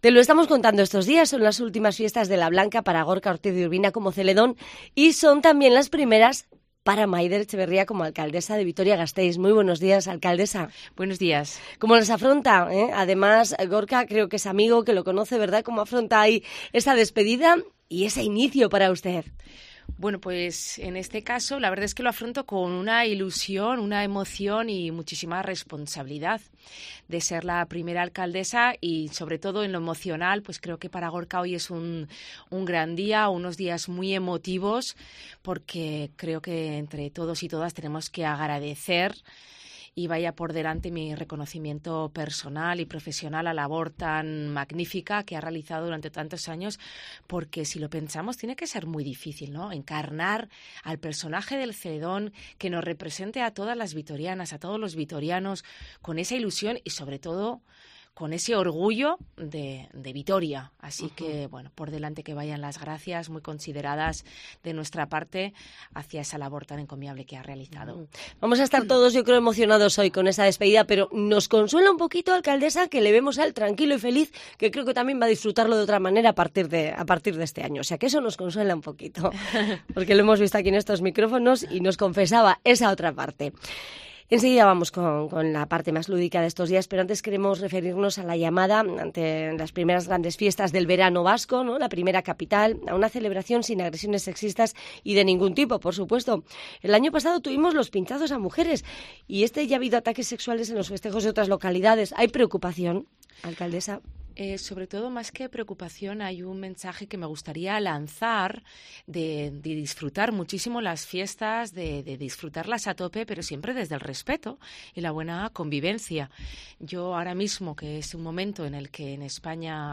Entrevista en COPE EUSKADI a Maider Etxebarria, alcaldesa de Vitoria